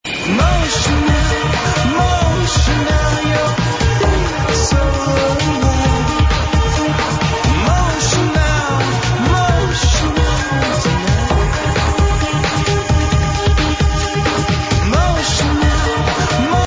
The vocalist sounds a bit like Iva Davies from Icehouse